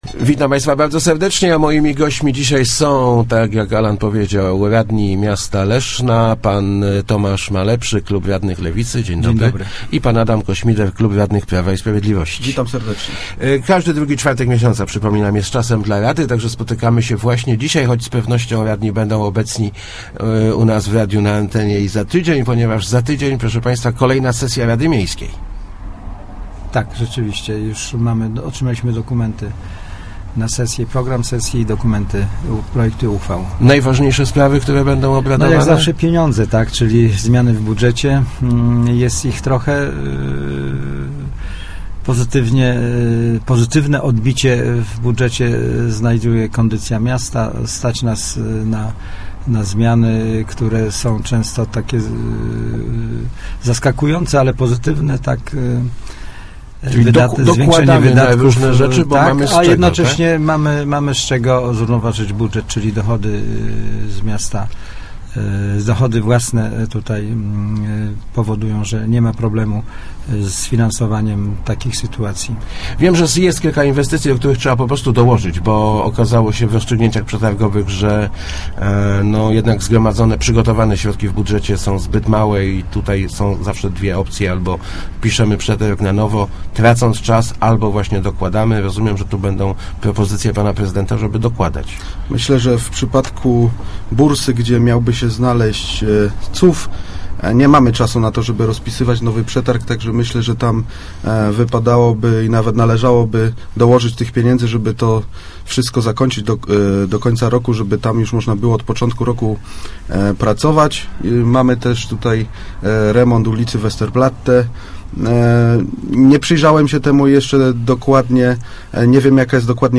O ewentualnym przy��czeniu Wilkowic do Leszna musz� zdecydowa� sami mieszka�cy – mówili w Kwadransie Samorz�dowym radni Leszna Tomasz Malepszy i Adam Ko�mider. Ich zdaniem w obu miejscowo�ciach powinno doj�� do referendum, cho� jak zaznaczyli, maj� obawy o frekwencj�.